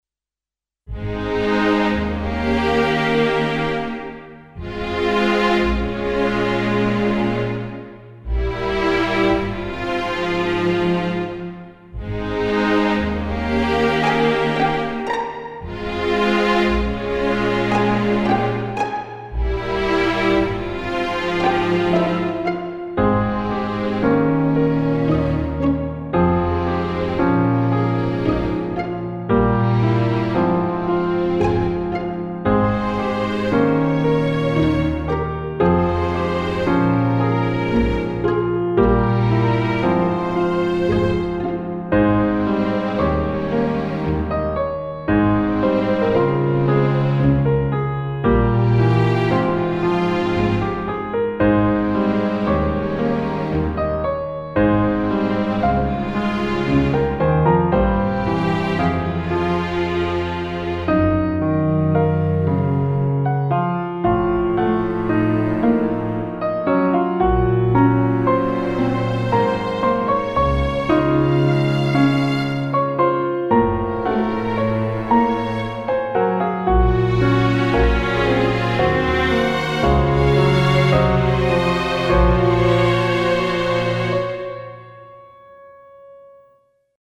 This piece was written for strings and piano.